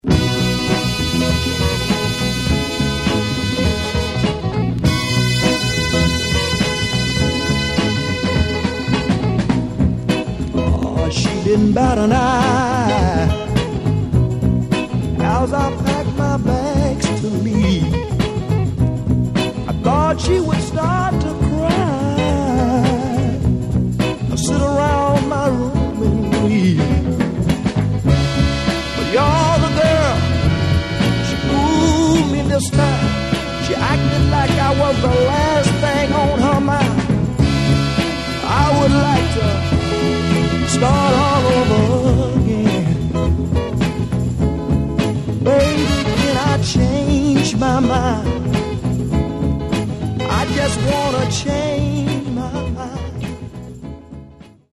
Genre: Other Northern Soul
slinky, catchy rhythm section